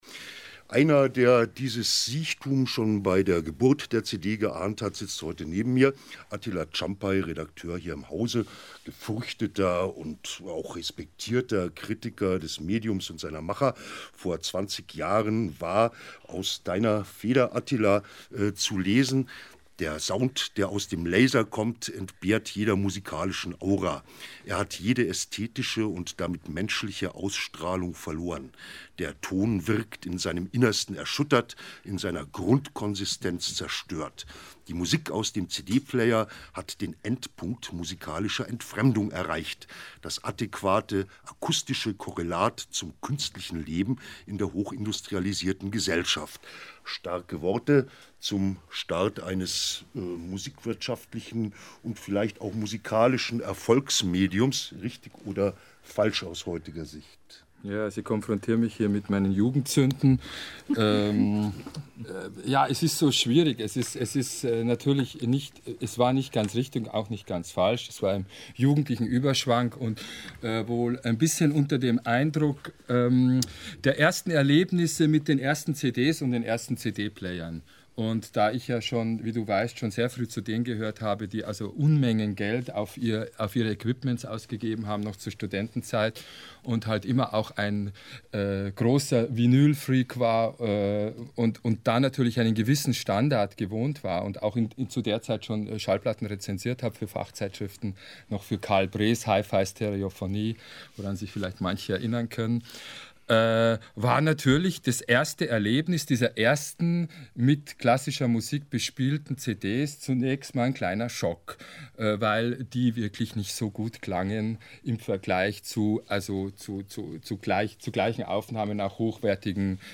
7.2.2003 / Bayern 2 Radio, 20:05 bis 21:00 Uhr Live aus dem Studio im Funkhaus München
02-Vorstellung-der-Gaeste.mp3